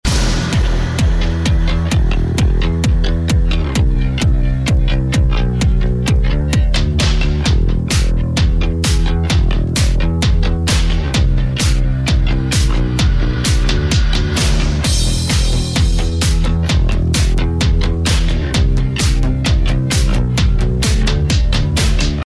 • Electronic Ringtones